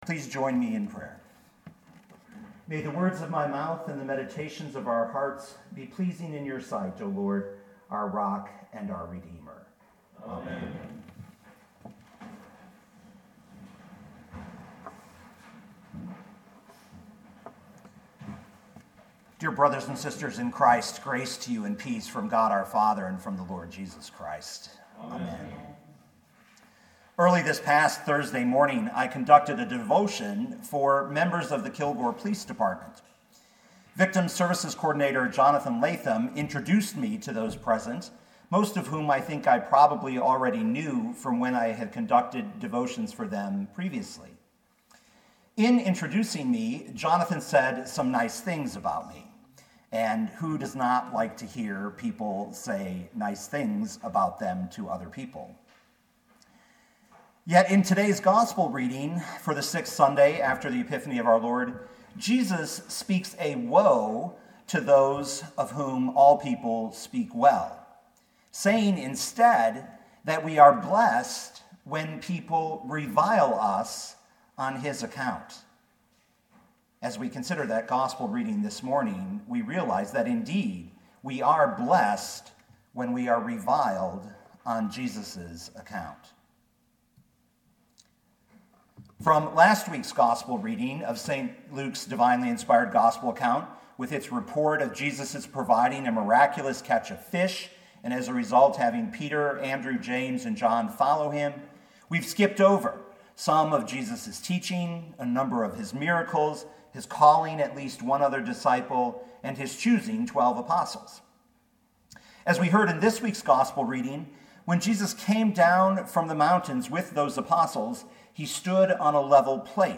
2019 Luke 6:17-26 Listen to the sermon with the player below, or, download the audio.